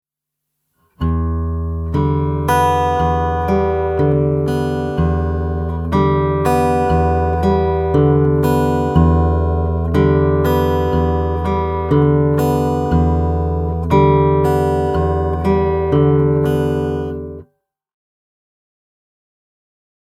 All of the rhythms in these examples have been kept fairly simple, and in 4/4 time.
You’ll notice from the examples that the thumb is basically playing quarter notes alternating between the bottom E string and the D string.
Alternating Bass Fingerstyle 4
audio for this alternating bass fingerstyle pattern.